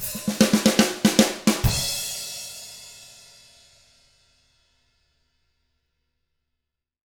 146ROCK E1-R.wav